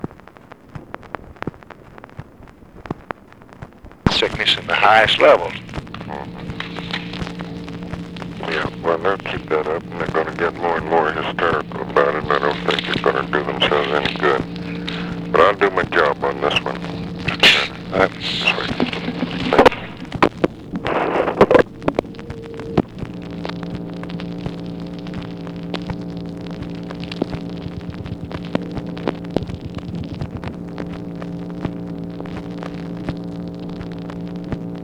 Conversation with ABE FORTAS, September 10, 1964
Secret White House Tapes